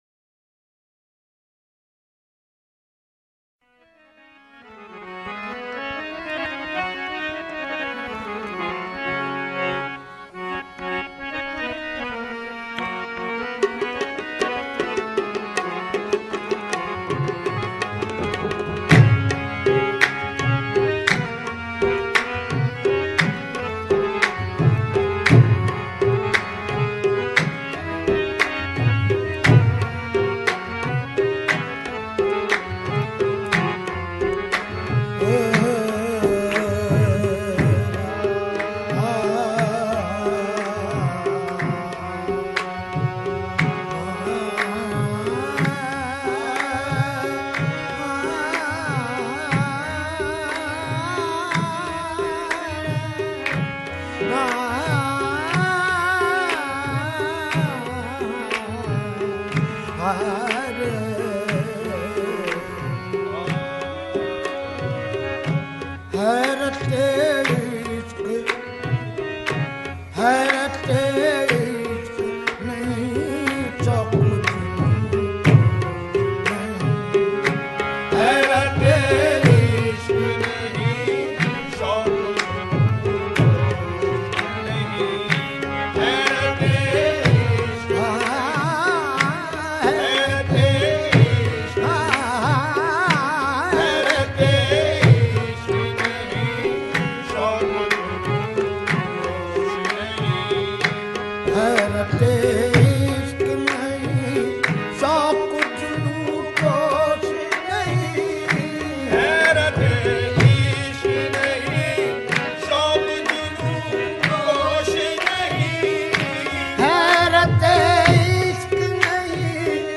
Qawwali
ashiqana kalam